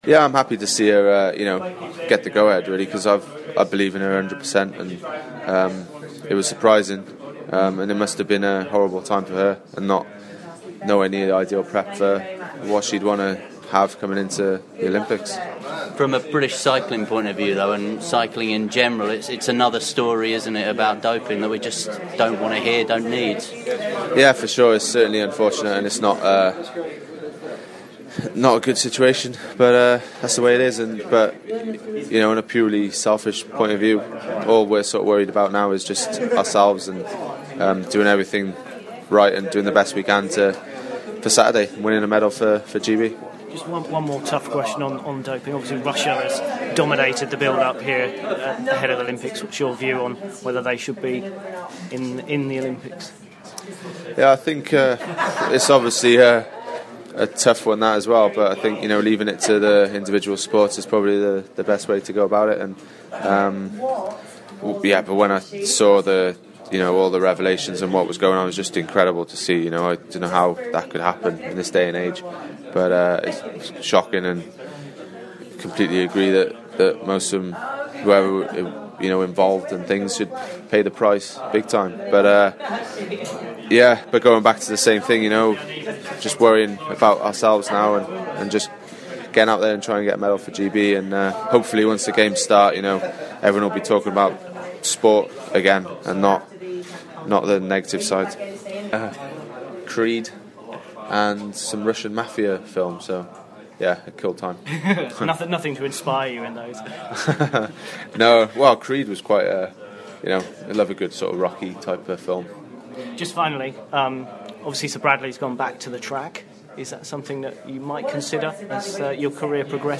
Team GB's Geraint Thomas talks to Radio Yorkshire about Yorkshire's Lizzie Armitstead being allowed to compete at Rio, he also talks about what he wants to achieve at the Olympics.